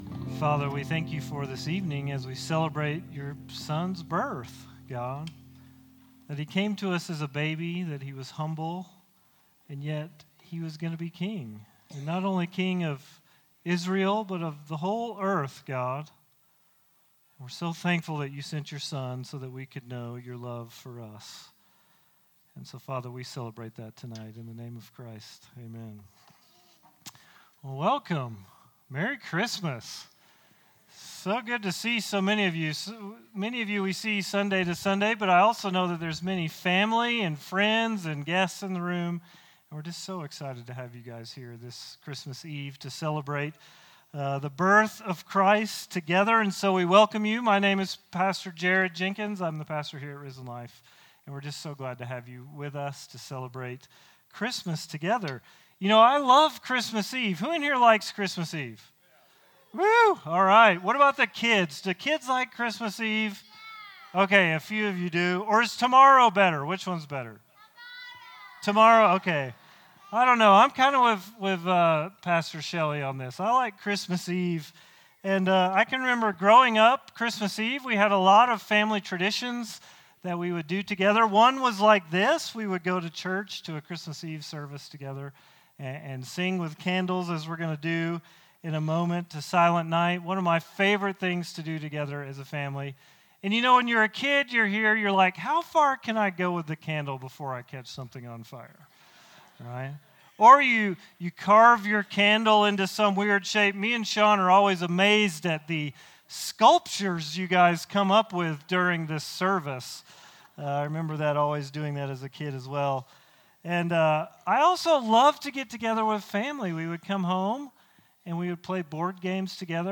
Join us as we celebrate the birth of Christ with our annual Christmas Eve service.